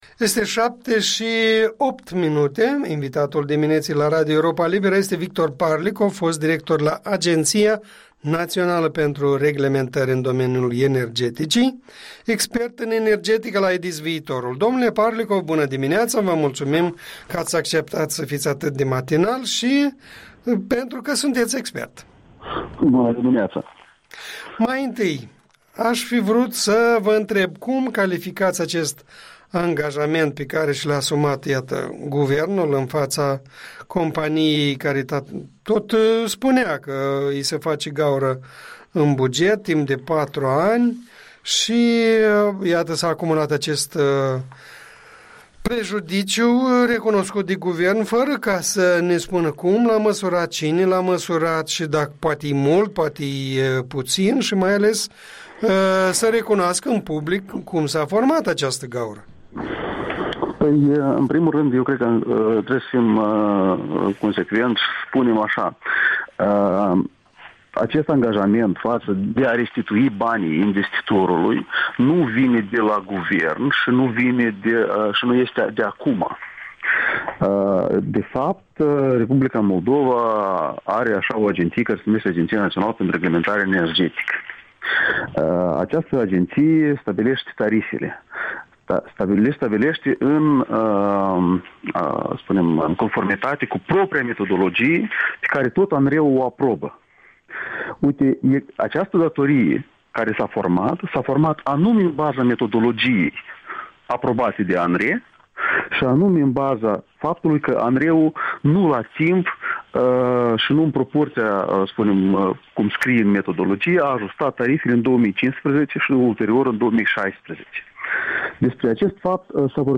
Interviul dimineții cu Victor Parlicov (IDIS, „Viitorul”)